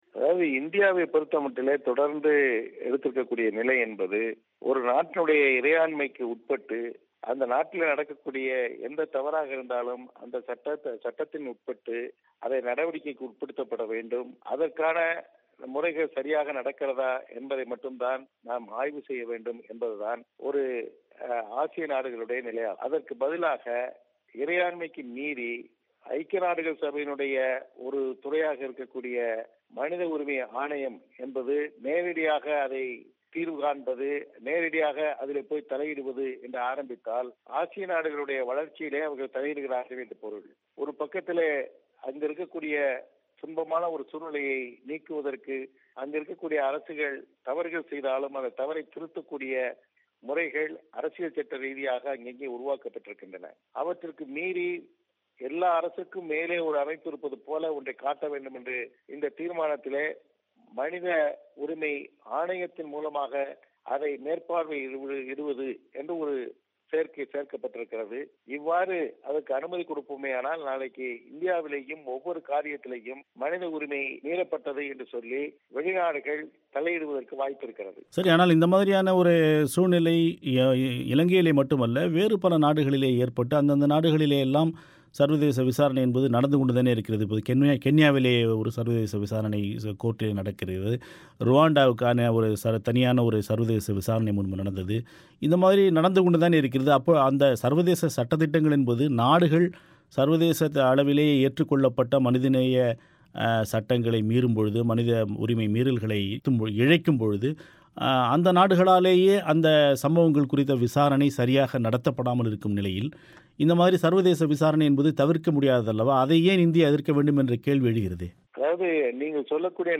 ஐநா மனித உரிமைகள் பேரவை ஊடாக இலங்கையின் இறையாண்மையில் தலையிடுகின்ற வெளிநாடுகள் எதிர்காலத்தில் இந்தியாவிலும் அதே காரணத்தைக் காட்டித் தலையிடலாம் என்ற காரணத்தினாலேயே இலங்கை மீதான தீர்மானத்தின் வாக்கெடுப்பிலிருந்து ஒதுங்கிக் கொண்டதாக மத்திய துணையமைச்சர் சுதர்சன நாச்சியப்பன் பிபிசி தமிழோசையிடம் கூறினார்.